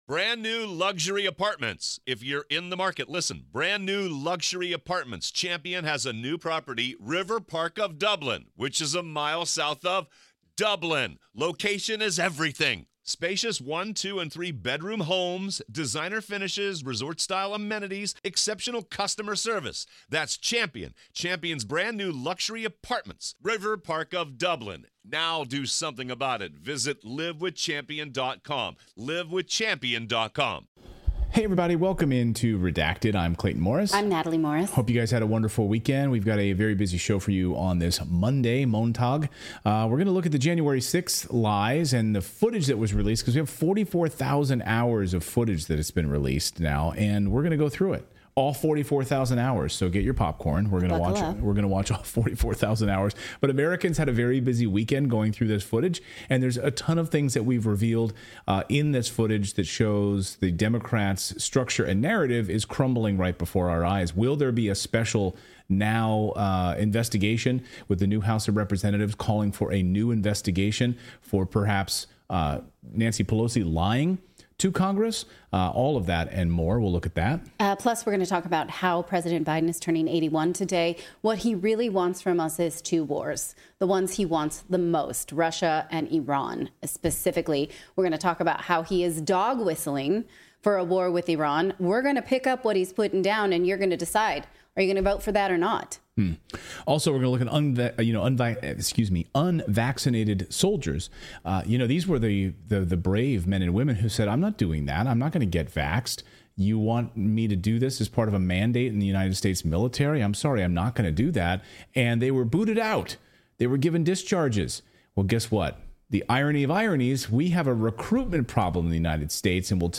The full batch of January 6th tapes are now available, more than 44,000 hours and boy do they show somethings Democrats wish were hidden. In this special live show we're going through the footage that completely alters the story we've been told.